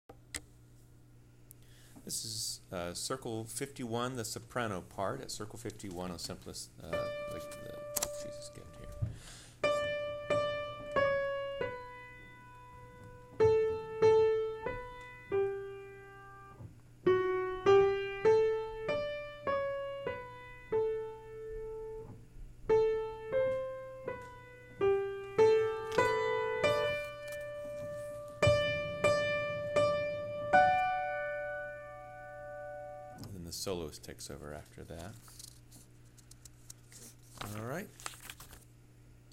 Soprano parts-starting at circle 51 and before circle 80
02 51 soprano part
02-51-soprano-part.wma